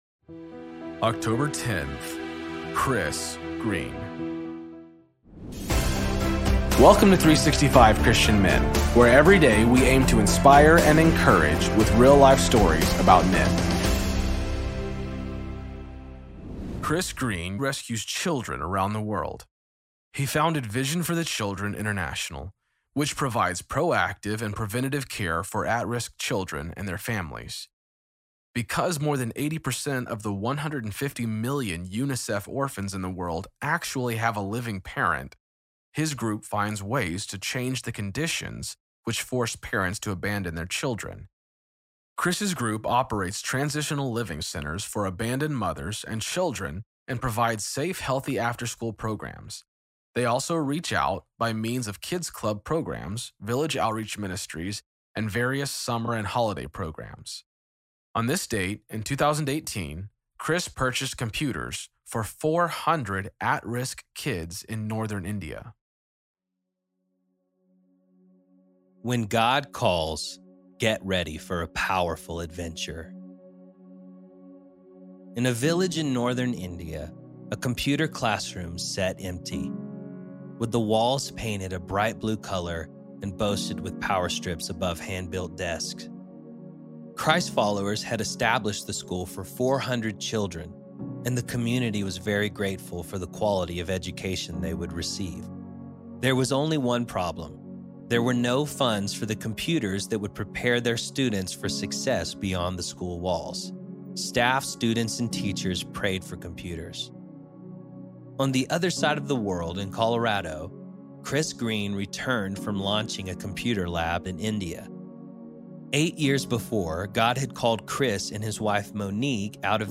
Story read by
Introduction read by